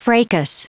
Prononciation du mot fracas en anglais (fichier audio)